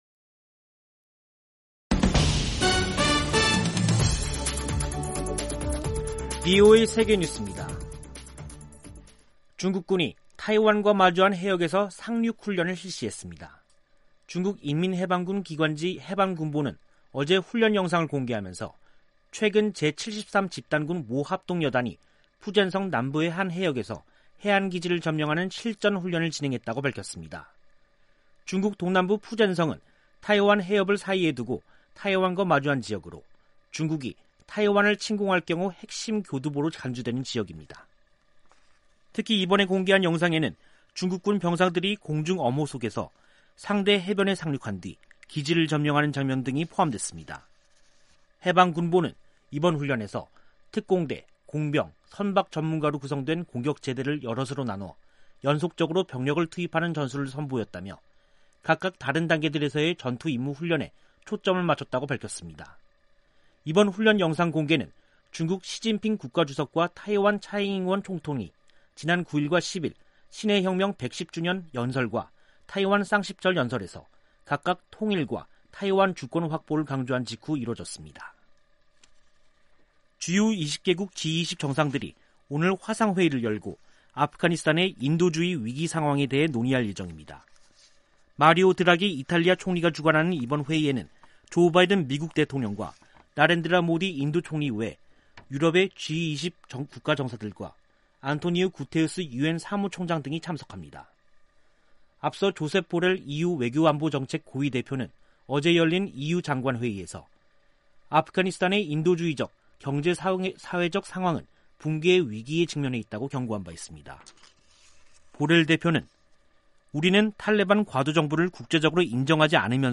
세계 뉴스와 함께 미국의 모든 것을 소개하는 '생방송 여기는 워싱턴입니다', 2021년 10월 12일 저녁 방송입니다. '지구촌 오늘'에서는 강경 반미 정파가 압승한 이라크 총선 소식, '아메리카 나우'에서는 텍사스주가 코로나 백신 의무화를 금지한 이야기 전해드립니다.